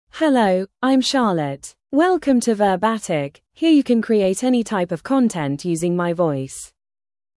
FemaleEnglish (United Kingdom)
CharlotteFemale English AI voice
Charlotte is a female AI voice for English (United Kingdom).
Voice sample
Charlotte delivers clear pronunciation with authentic United Kingdom English intonation, making your content sound professionally produced.